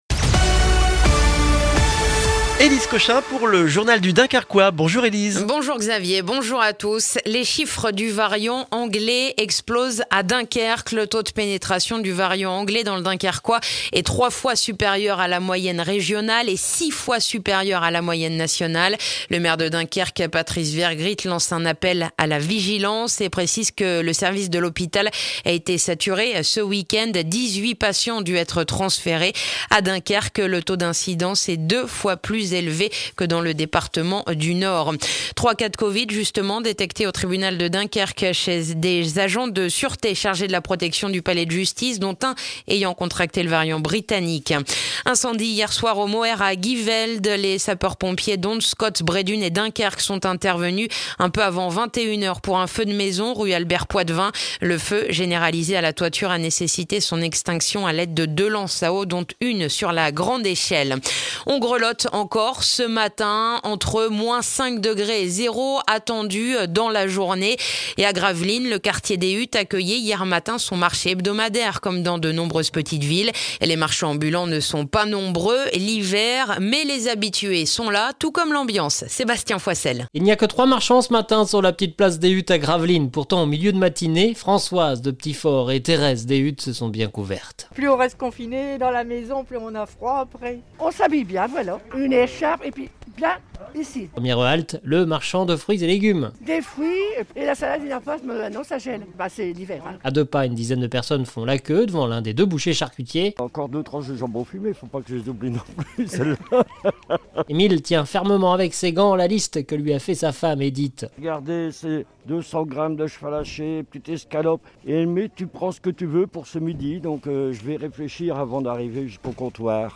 Le journal du jeudi 11 février dans le dunkerquois